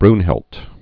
(brnhĭlt)